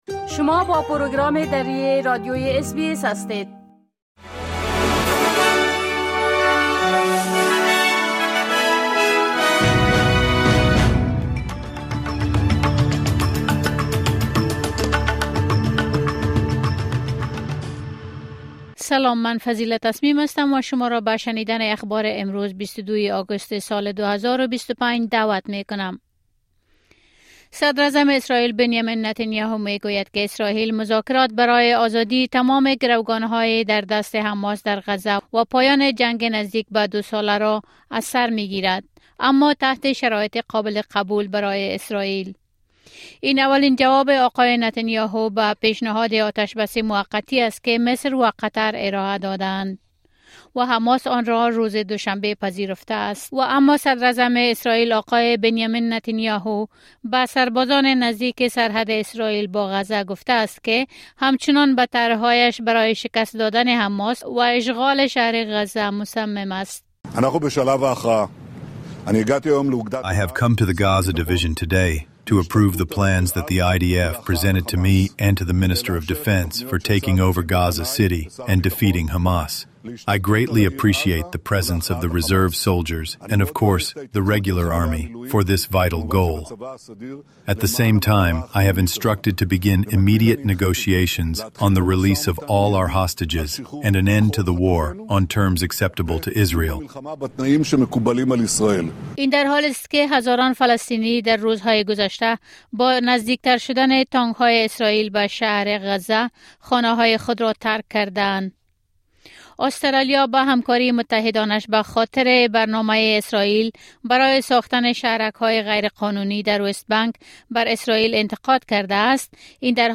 خلاصه مهمترين خبرهای روز از بخش درى راديوى اس‌بى‌اس ۲۲ اگست